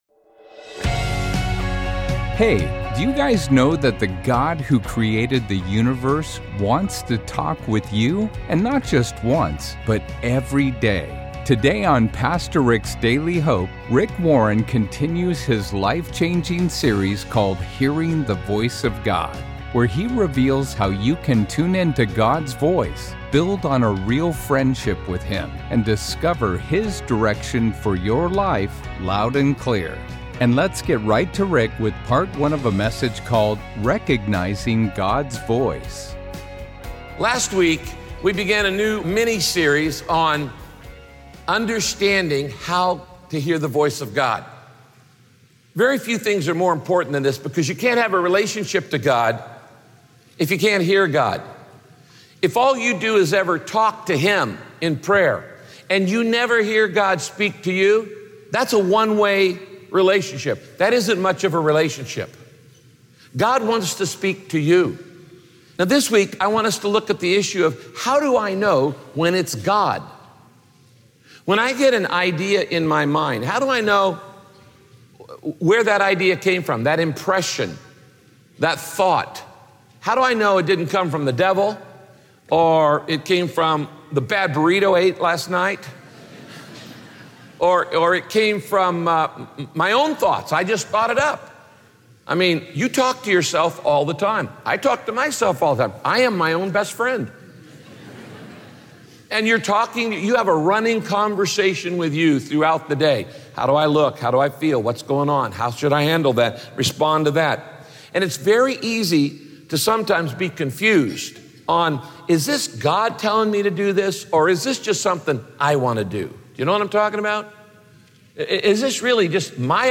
We often wonder if an idea is instruction from God, deception from Satan, or just something we want to do. In this teaching, Pastor Rick teaches you how to disc…